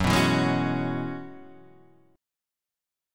F# 7th Suspended 2nd Suspended 4th